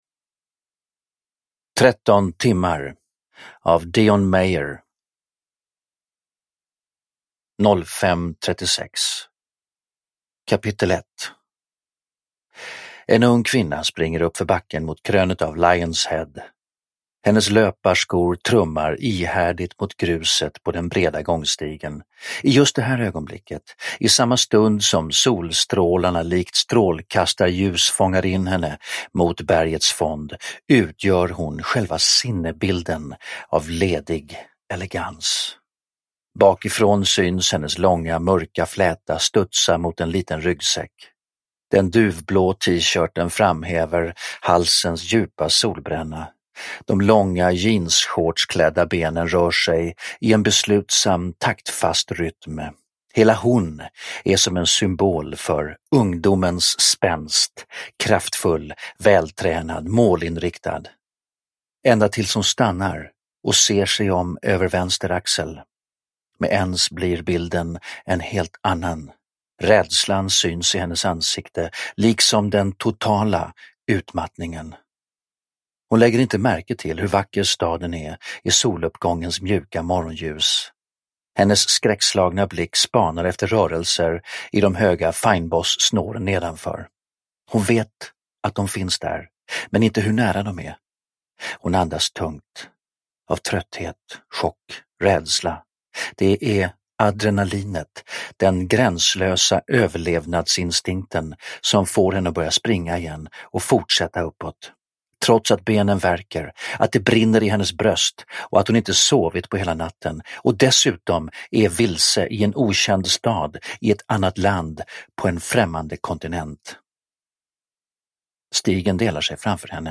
Tretton timmar – Ljudbok – Laddas ner
Uppläsare: Stefan Sauk